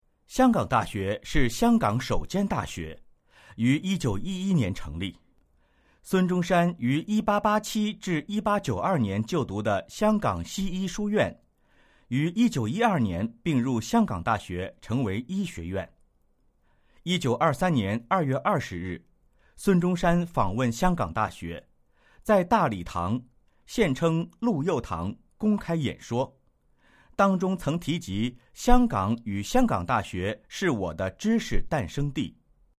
语音简介